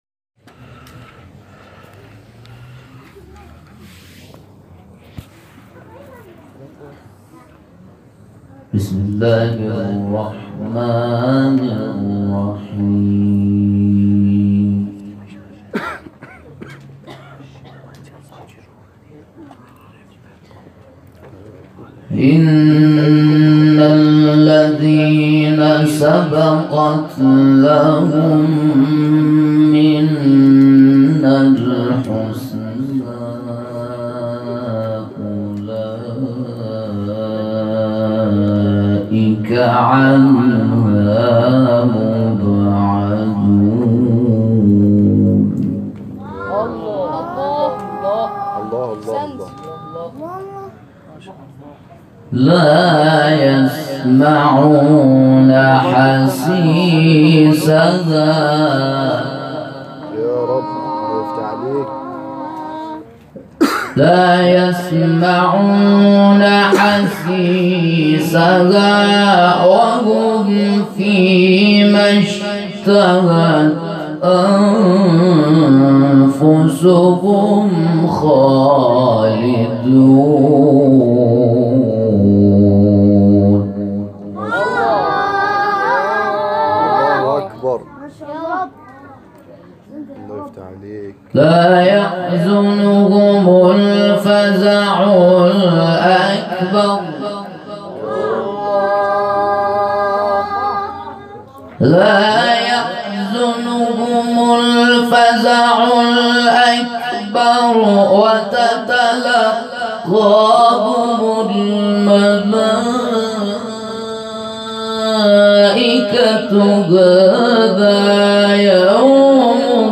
گروه جلسات و محافل: کرسی تلاوت رضوی همزمان با ایام دهه کرامت و ولادت حضرت علی بن موسی الرضا(ع) در حسینیه ثقلین کرمانشاه در برگزار شد.